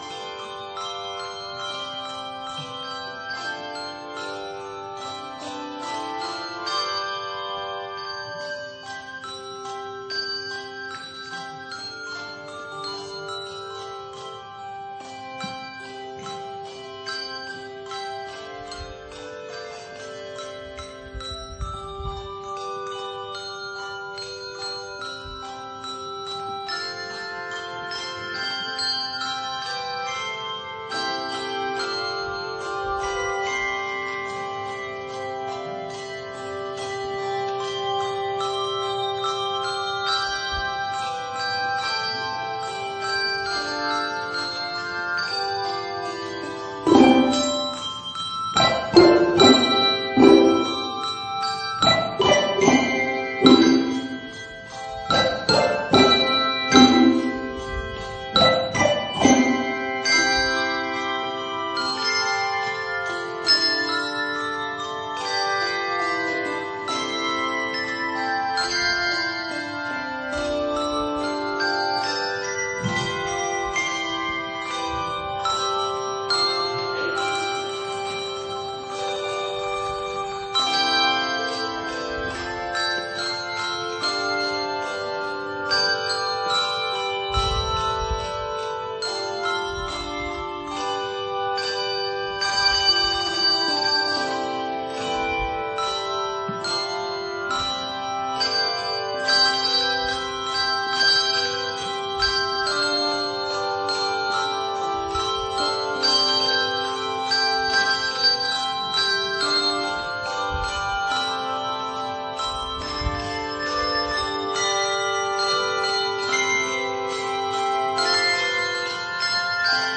Sermons Archive - Faith Community United Methodist Church - Xenia, OH